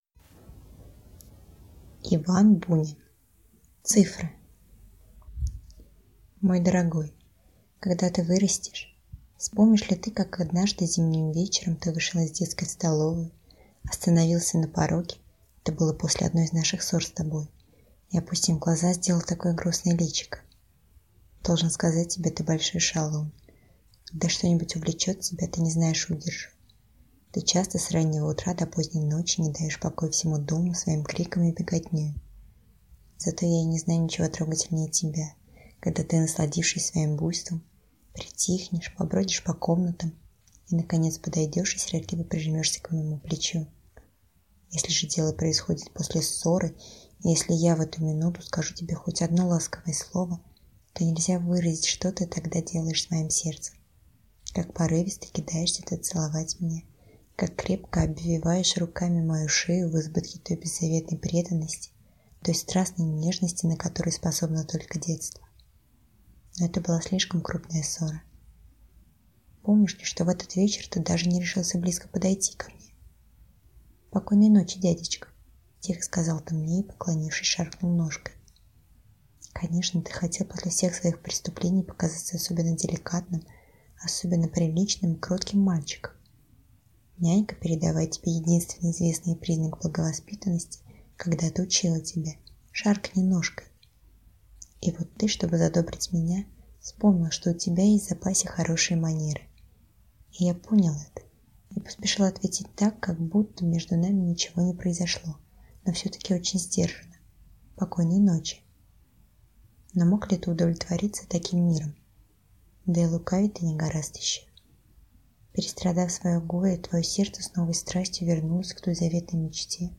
Аудиокнига Цифры | Библиотека аудиокниг